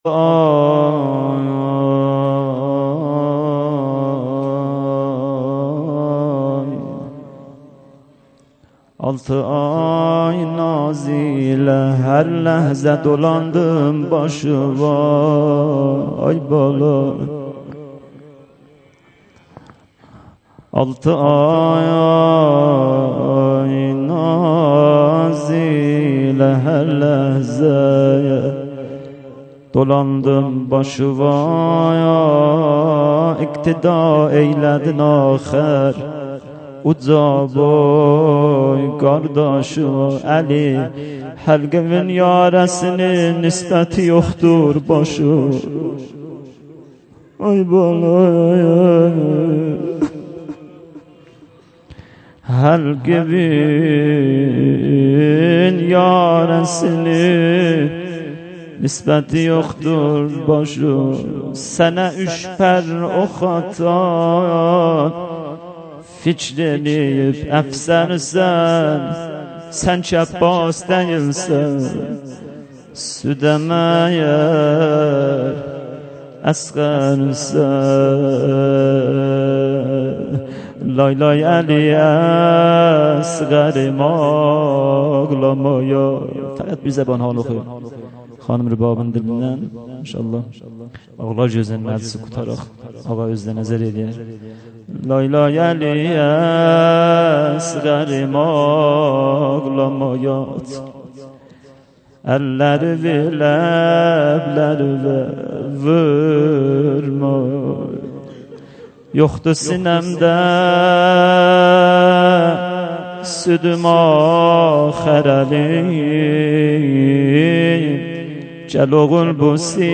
هیأت محبان اهل بیت علیهم السلام چایپاره
محرم 97 - شب هفتم